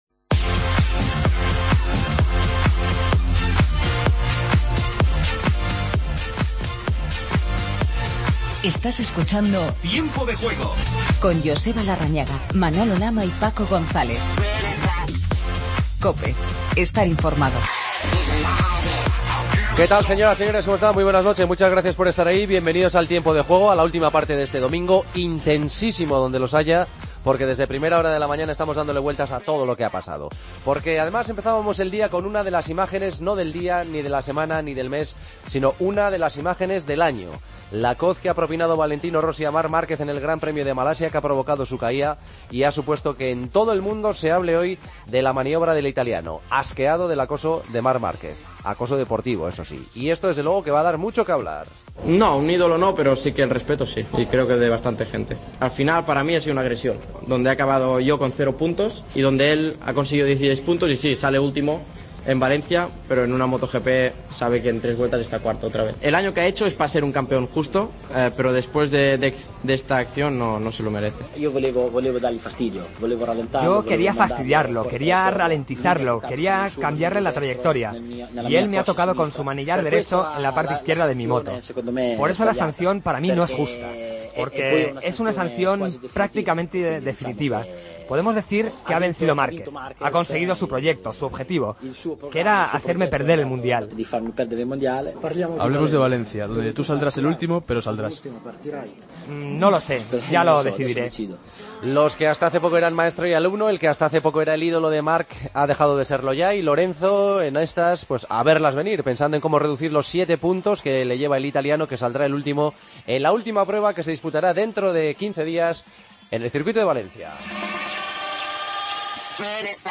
El Atlético ganó al Valencia (2-1) con un gol de Jackson Martínez. Entrevistamos al delantero colombiano.